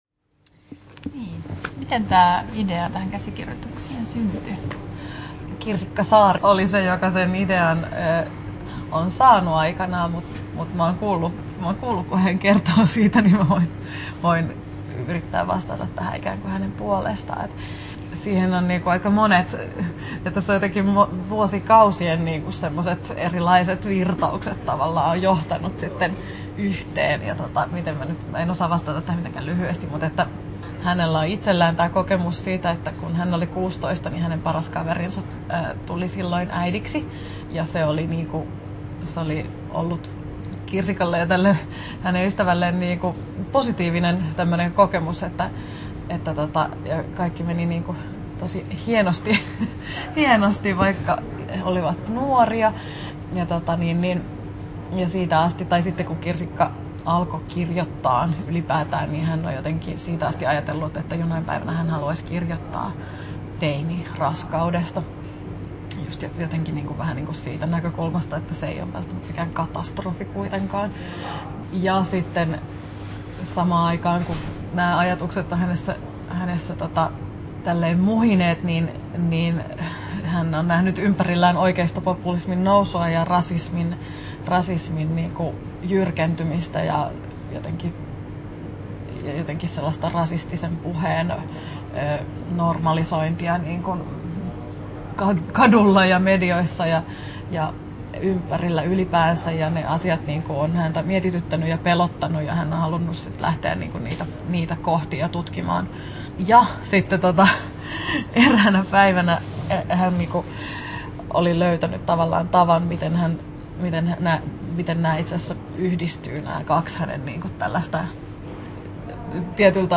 Haastattelut
16'46" Tallennettu: 4.10.2018, Turku Toimittaja